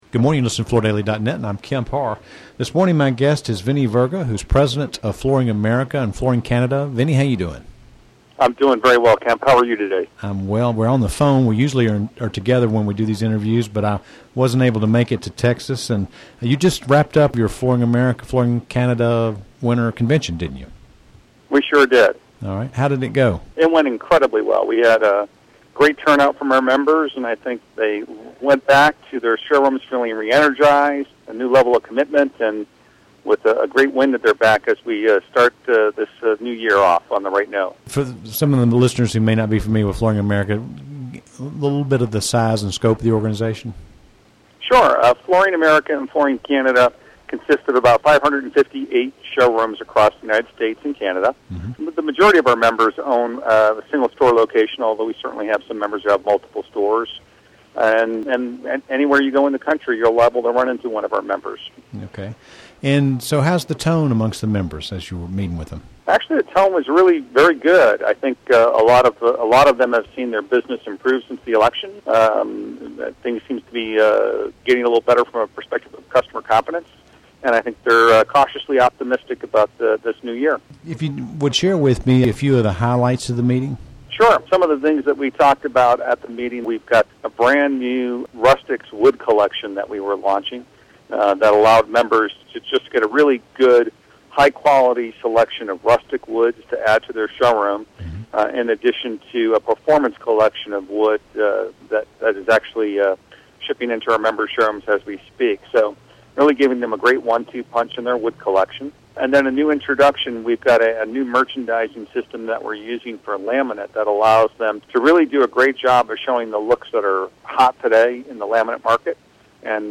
Listen to the interview to hear more details and also learn about consumer research that reveals what today's consumer is looking for when she buys flooring.